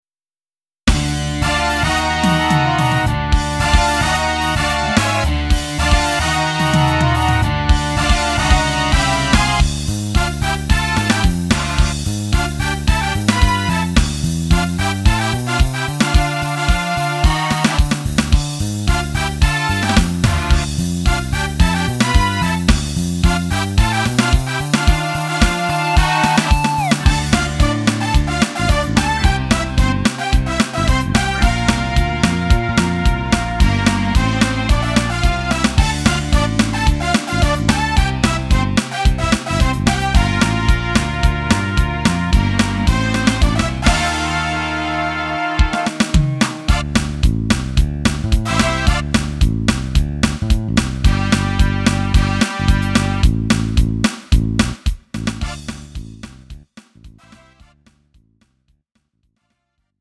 음정 -1키 3:49
장르 가요 구분 Lite MR